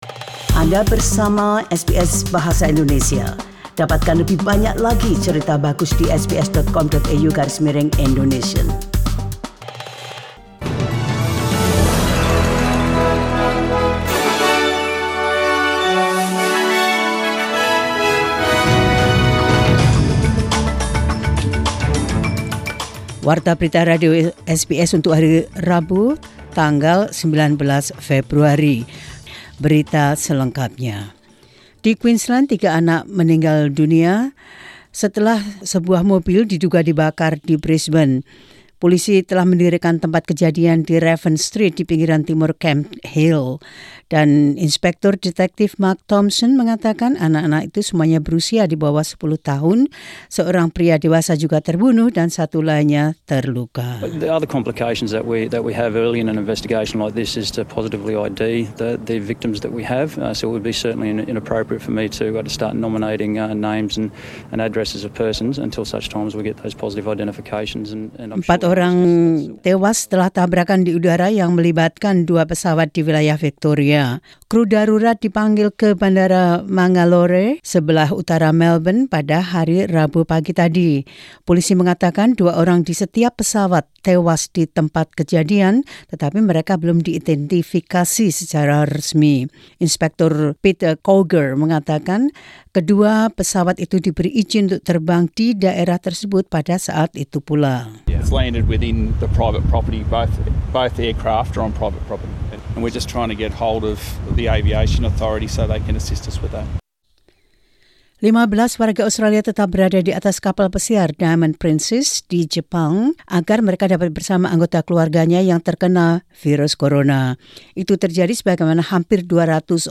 SBS Radio News in Indonesian 19 Feb 2020.